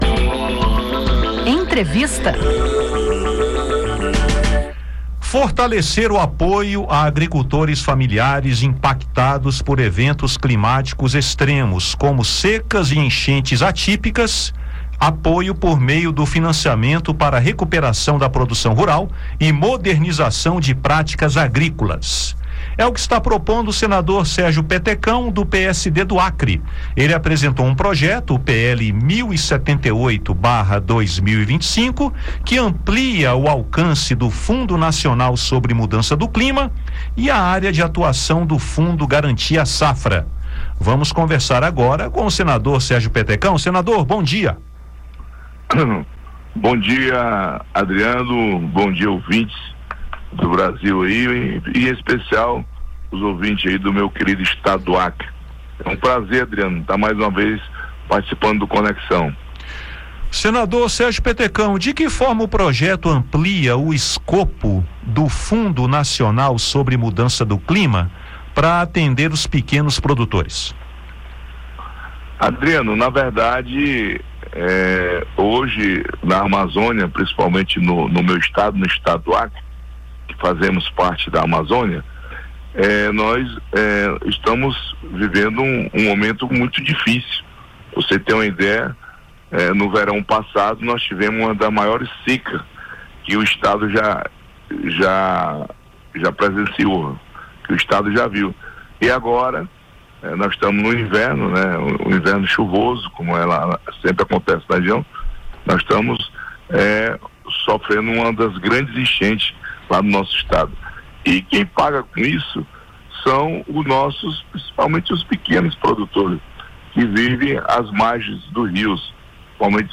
Em entrevista nesta quinta-feira (20), Petecão explicou como essas mudanças podem beneficiar os pequenos produtores, principalmente na Região Norte.